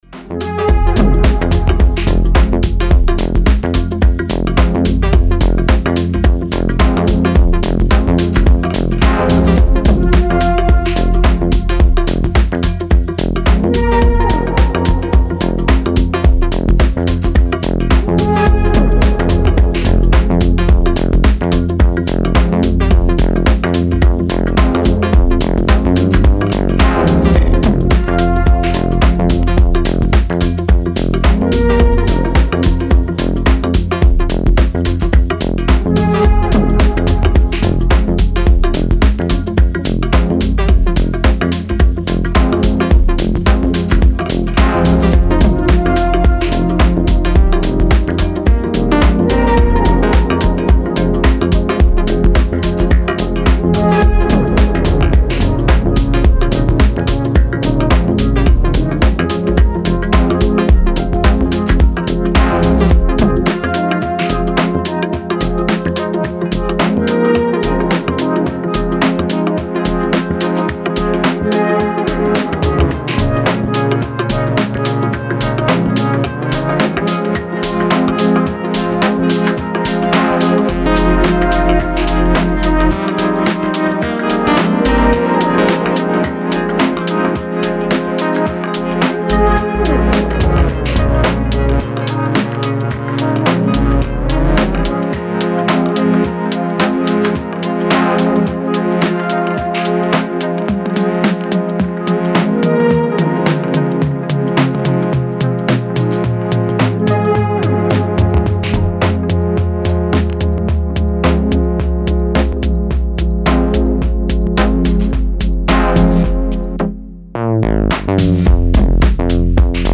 Style: Tech House / Minimal